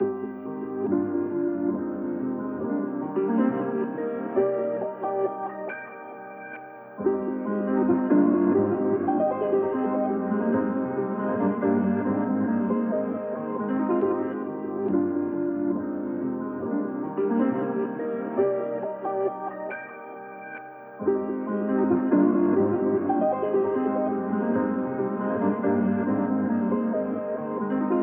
14 Trap Loops created in the style of: Drake, Future, Travis Scott, A$ap Ferg, 21 Savage, Lil Baby, 2 Chainz, Juice WRLD and More!
Perfect for Trap, but works well with R&B, Pop and similar genres too.
• High-Quality Trap Samples 💯